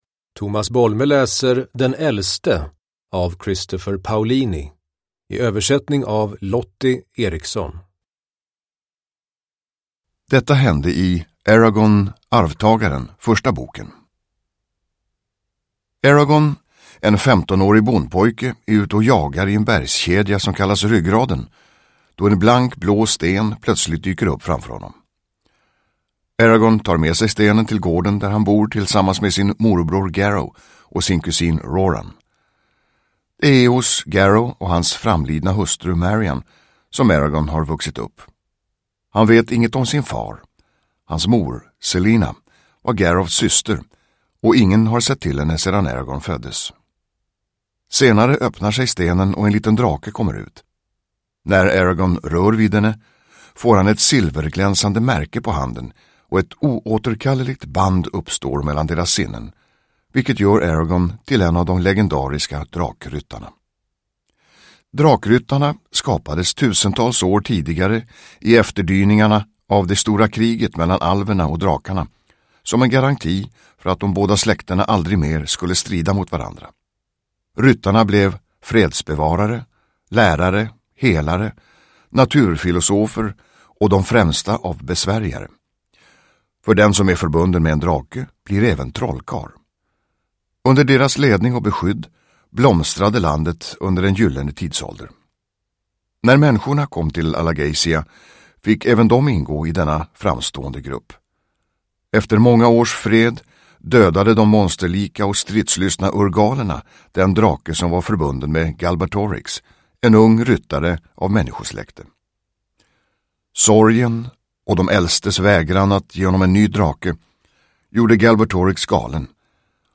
Uppläsare: Tomas Bolme